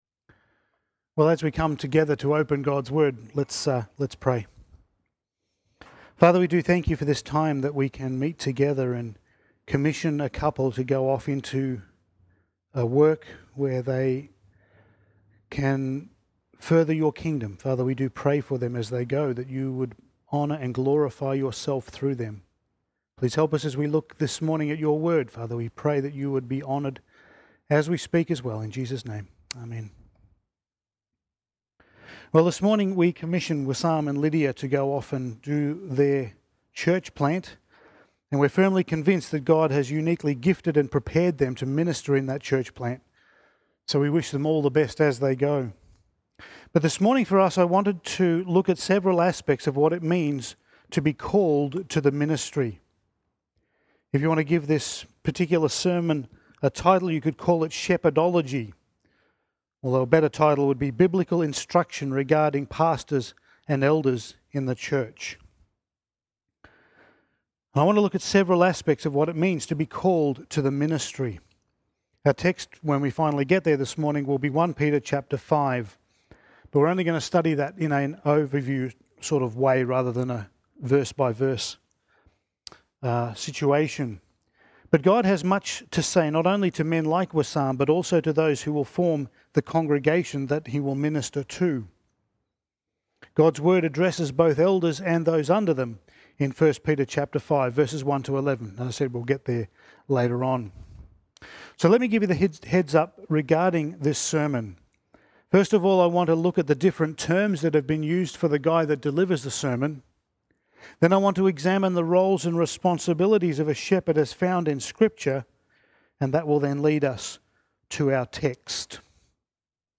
Topical Sermon
Service Type: Sunday Morning